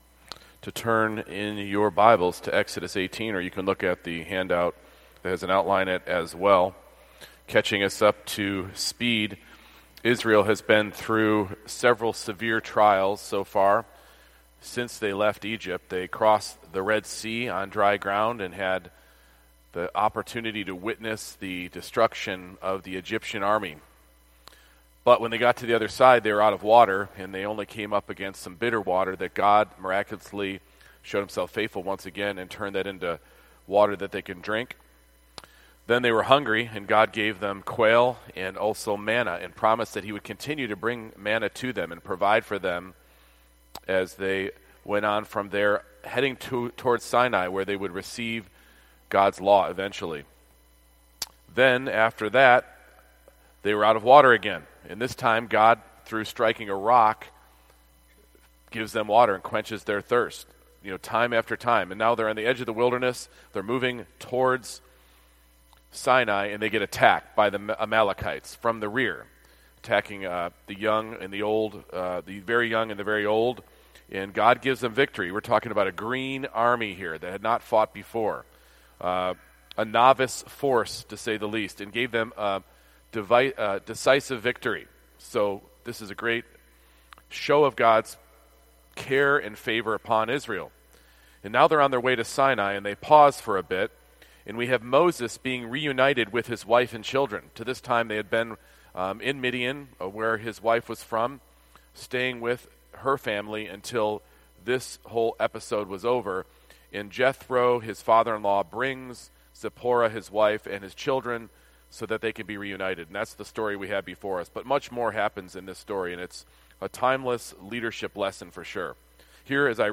Service Type: Sunday Evening Service